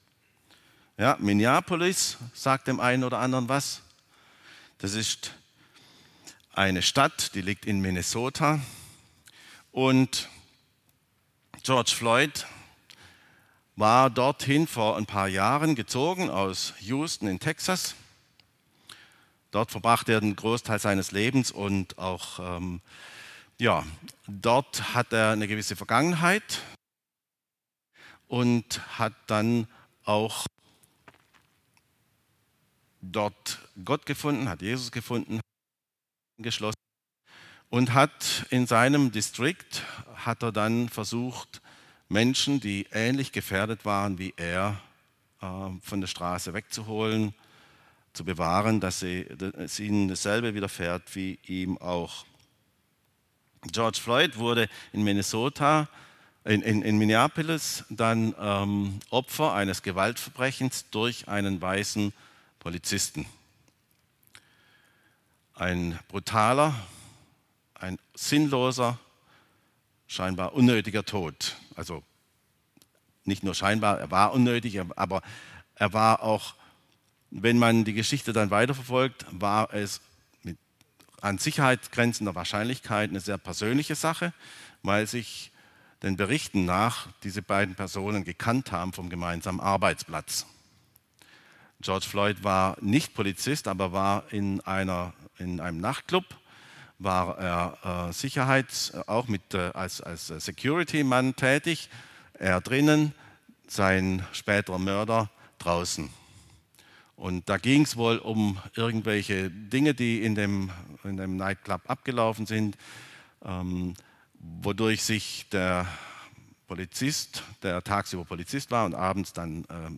regiogemeinde - Predigten